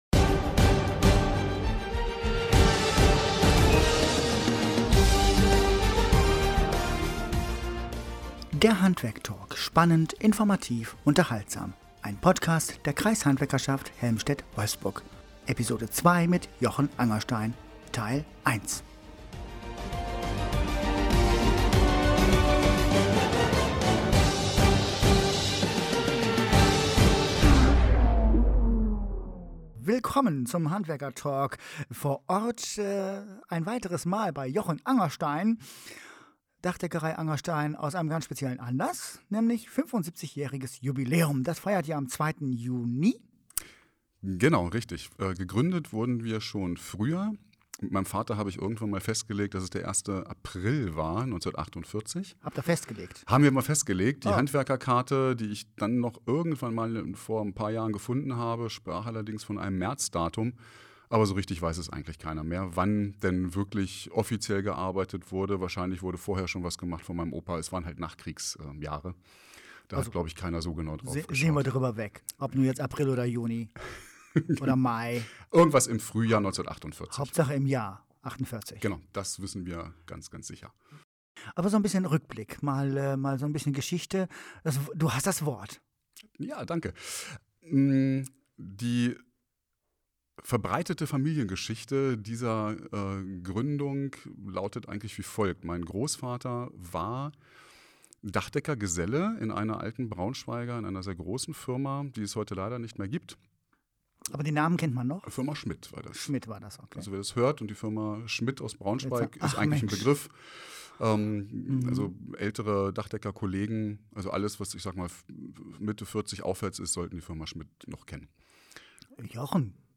75 Jahre Dachdeckerei – was einst mit einem Handkarren begann ist heute ein moderner Dachdecker-Betrieb, eine GmbH. Im Gespräch